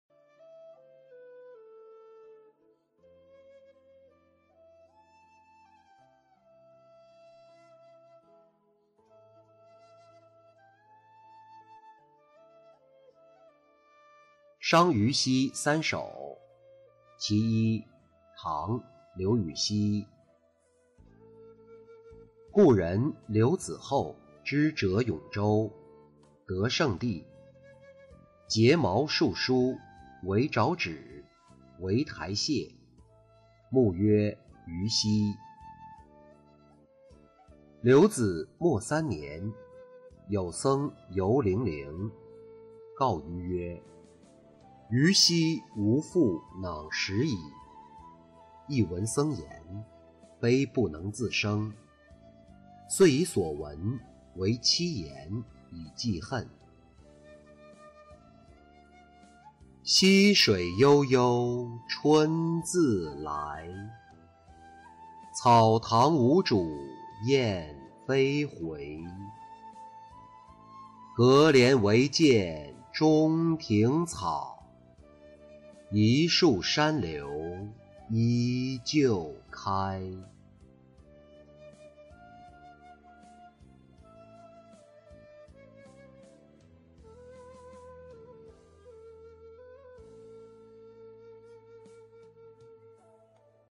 伤愚溪三首·其一-音频朗读